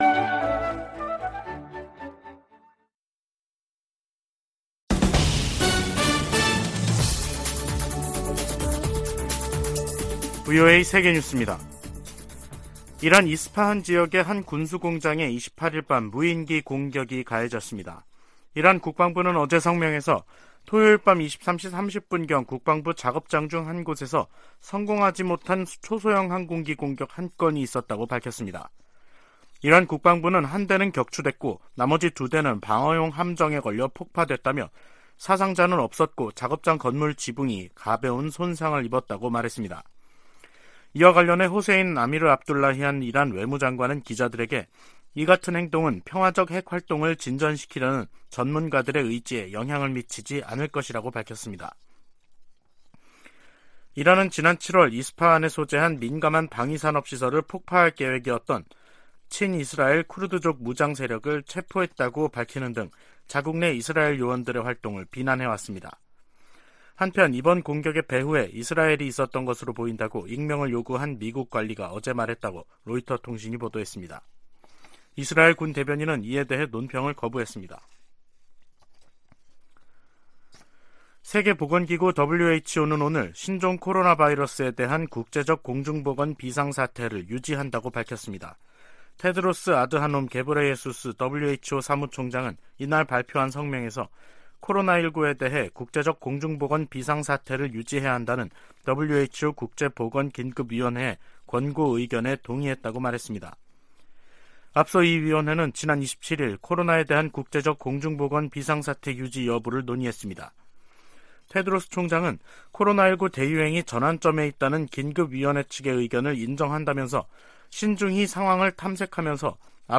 VOA 한국어 간판 뉴스 프로그램 '뉴스 투데이', 2023년 1월 30일 2부 방송입니다. 백악관은 북한이 ‘사이버 업계 전반의 취약한 보안으로 10억 달러 이상을 탈취해 미사일 프로그램에 자금을 조달하는 것이 가능했다’고 지적했습니다. 서울에서 열리는 미한 국방장관 회담에서 확장억제 실행력 강화 방안이 집중 논의될 것이라고 미 군사 전문가들이 전망했습니다.